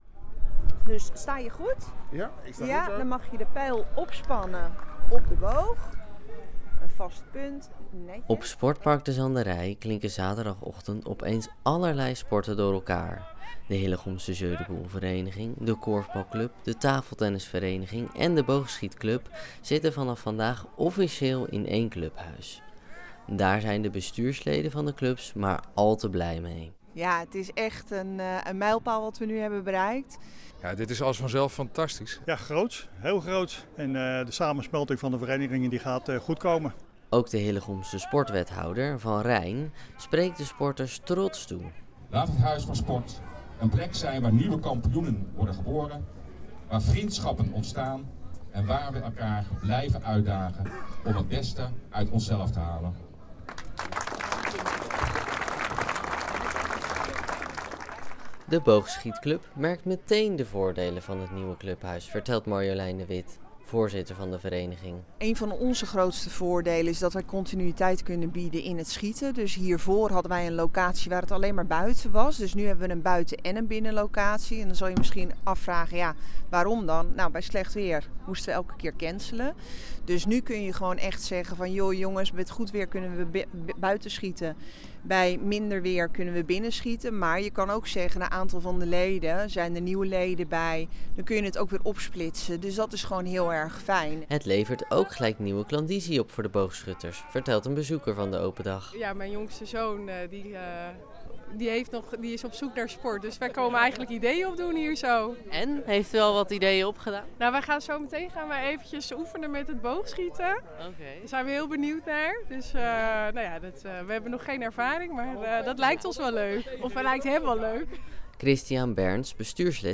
Trots spreekt hij met oud-burgemeester Arie van Erk en nieuwe burgemeester Roberto ter Hark aan zijn zijde de sporters toe: “Laat het Huis van Sport een plek zijn waar kampioenen worden geboren, vriendschappen ontstaan en waar we elkaar blijven uitdagen om het beste uit onszelf te halen.”